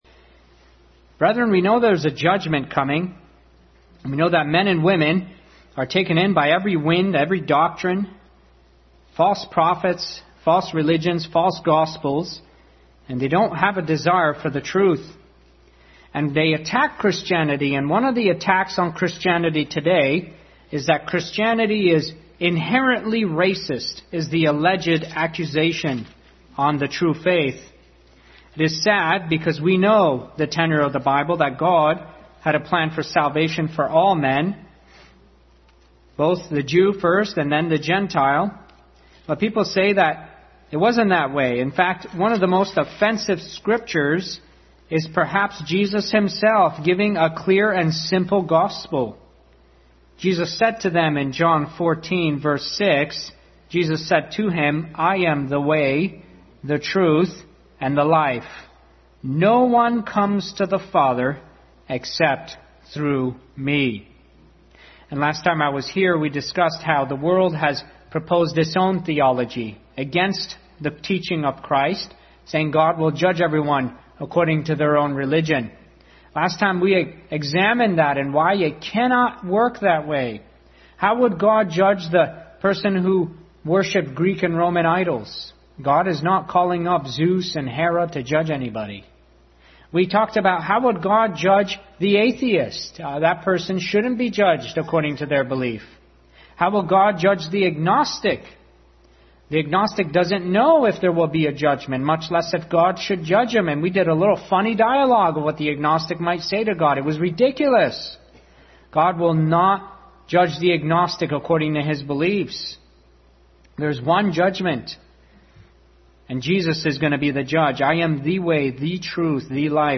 One Way Passage: John 14:6, 7:37, 10:7-11, Romans 10:8-9, 1 Timothy 2:1-7 Service Type: Family Bible Hour Family Bible Hour message.